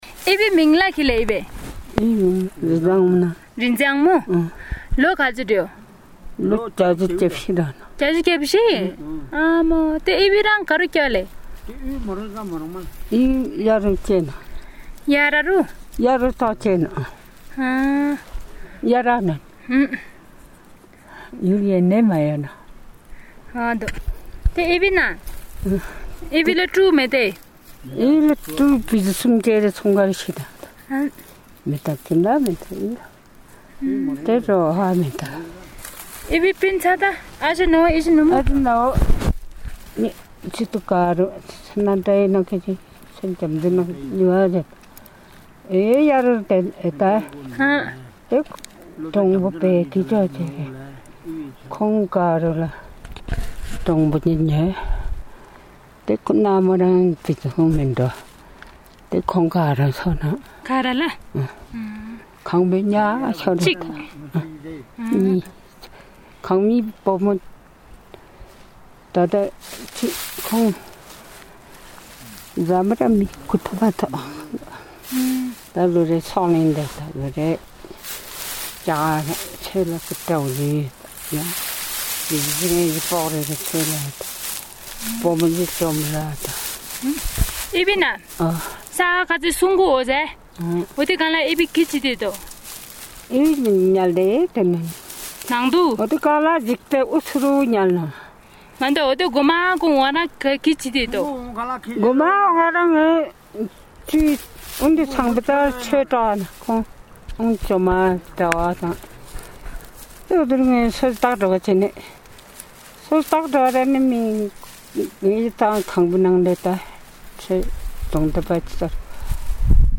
Interview of a community member on the 2015 Nepal Earthquakes
Audio Interview